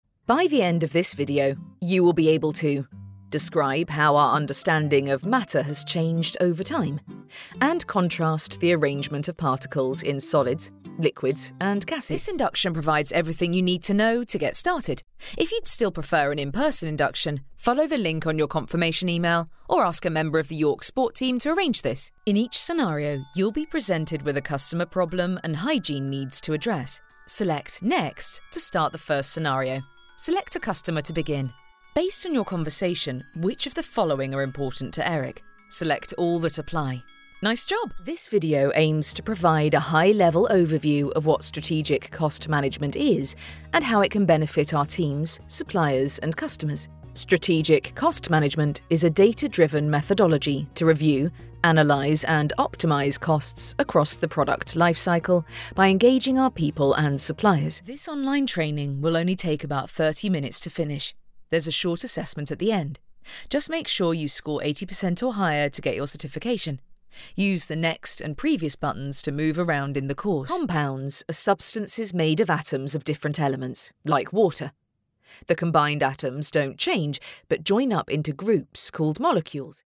Female
English (British)
My natural vocal tone is sincere, friendly and direct with a clarity and warmth.
E-Learning